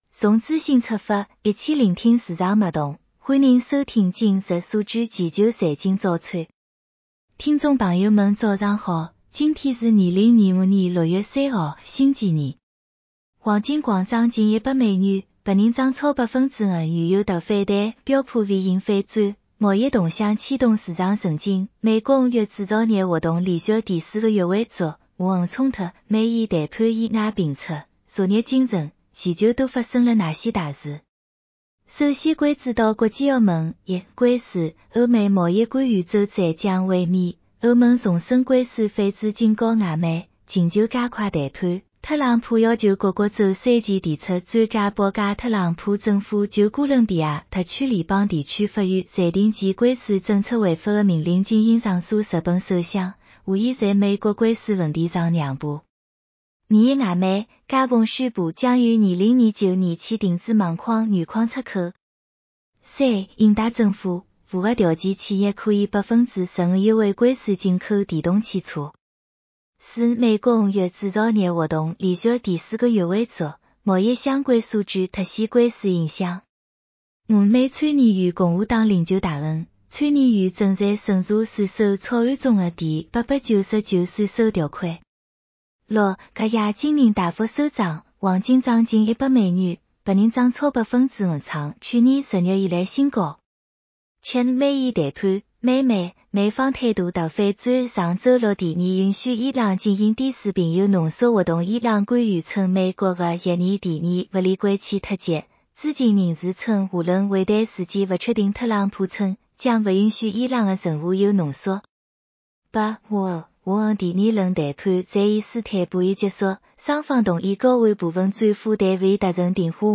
上海话版 下载mp3